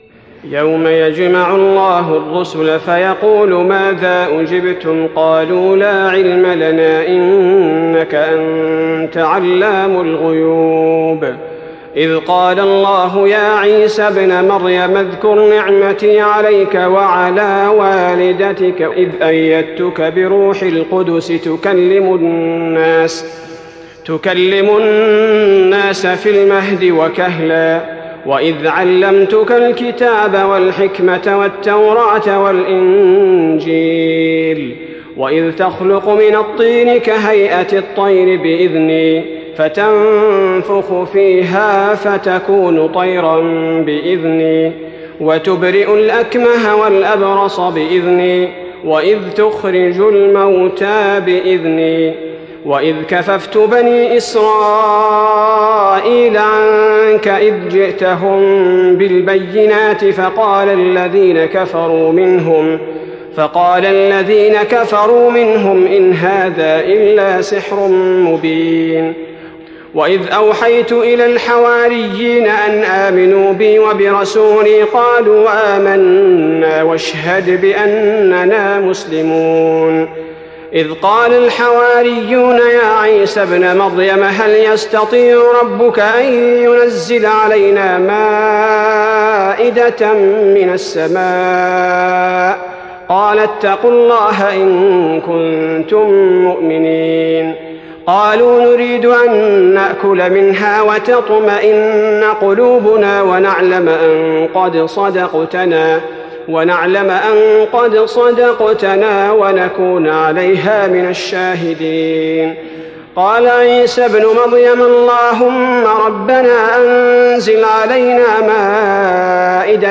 تراويح رمضان 1415هـ من سورة المائدة (109-120) Taraweeh Ramadan 1415H from Surah AlMa'idah > تراويح الحرم النبوي عام 1415 🕌 > التراويح - تلاوات الحرمين